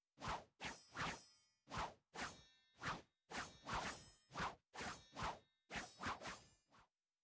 03_书店外黄昏_舞刀声音.wav